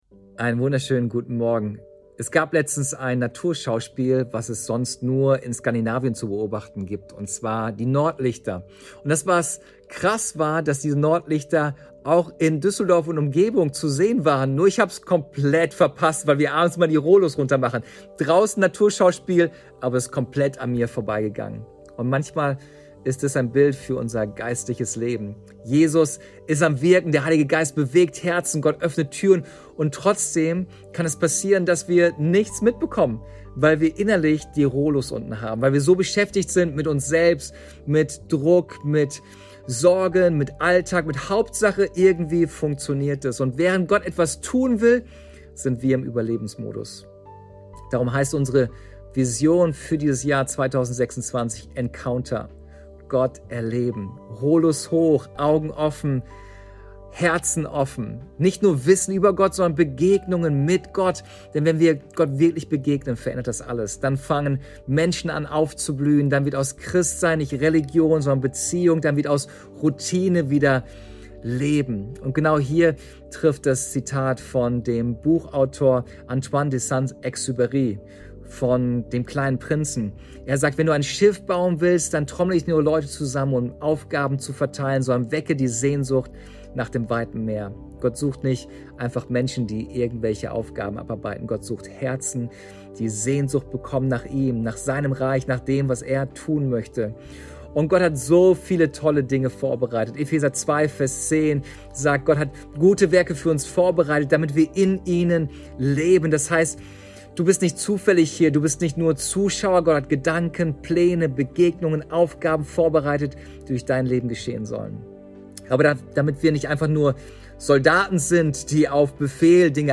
Tag 20 der Andacht zu unseren 21 Tagen Fasten & Gebet